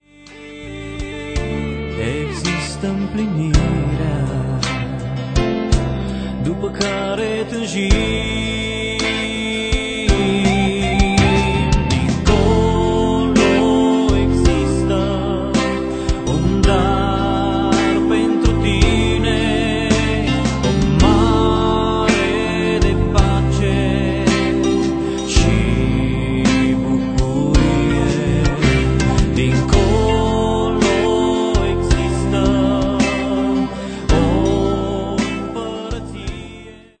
si vocile de exceptie.